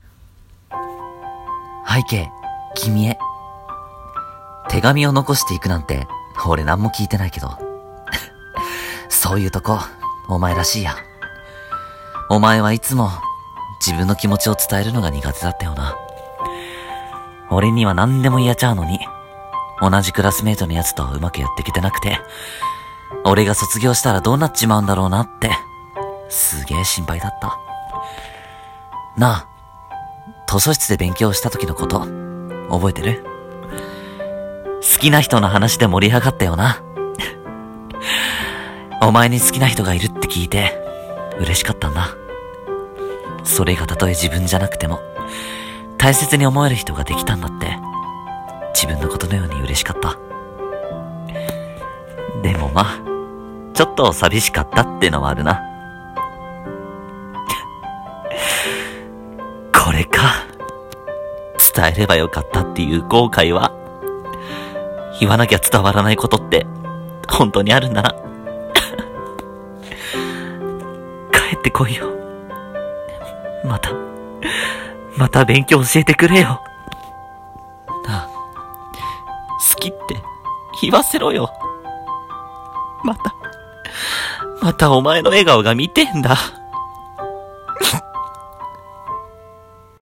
【声劇】 バカな後輩へ、さようなら。